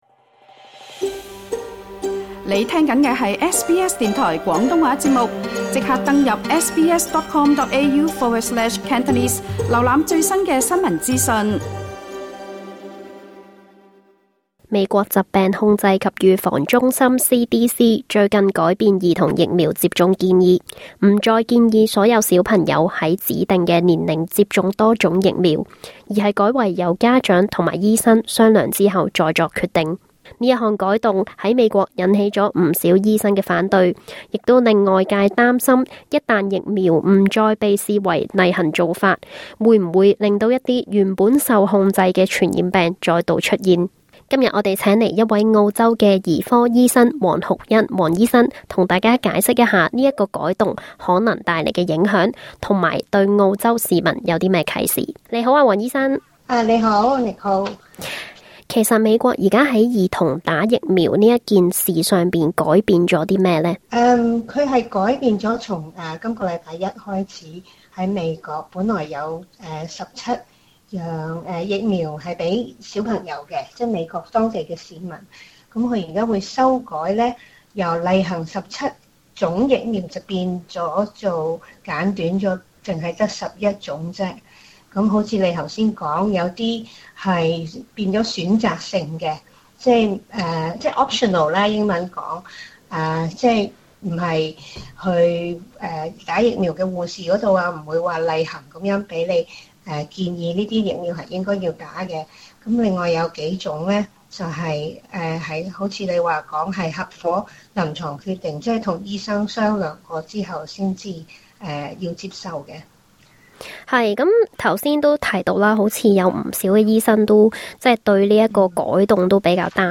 她也在訪問中解答了，美國改變疫苗制度的風險，及澳洲會否可能受到影響。詳情請收聽足本訪問。